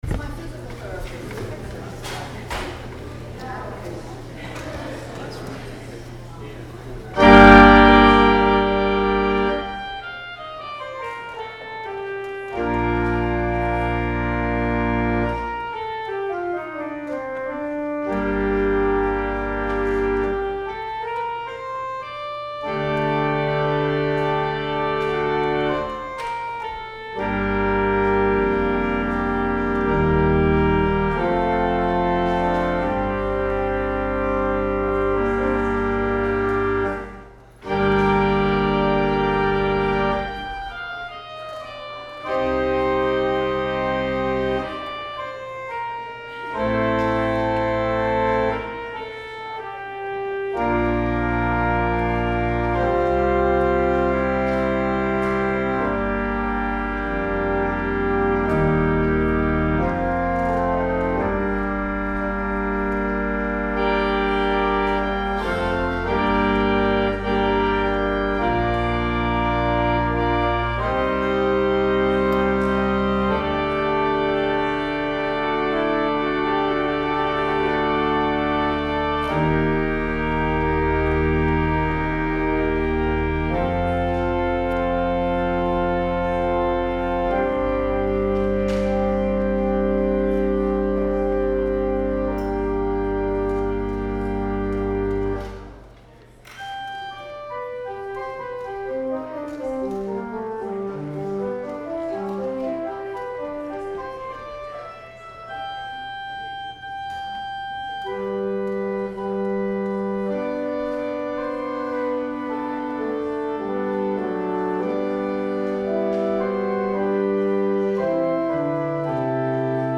Music from June 2, 2019 Sunday Service